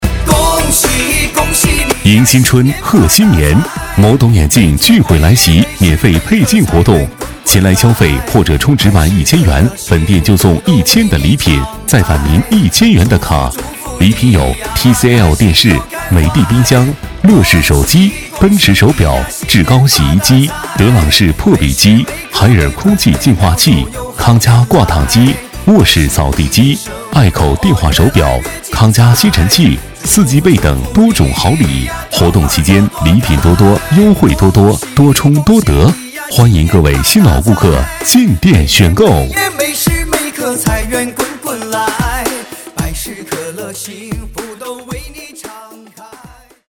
B类男01
【男1号促销】眸懂眼镜